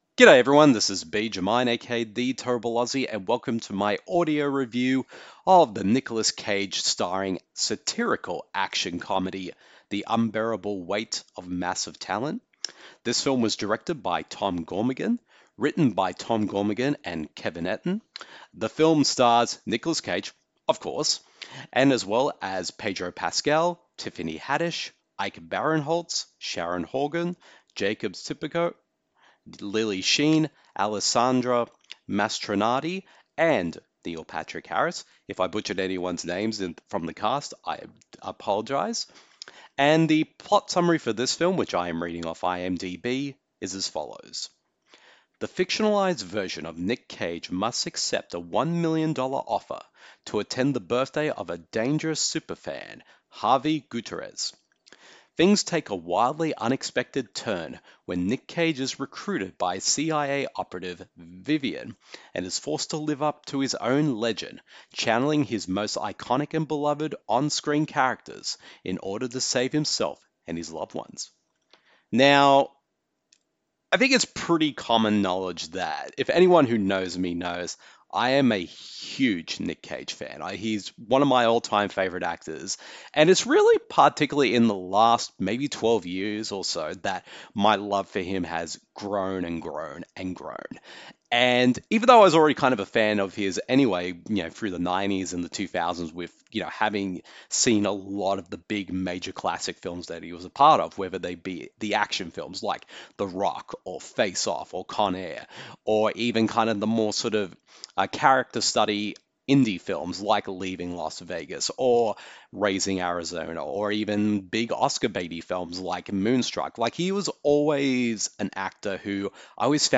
The following review of the film is in an audio format.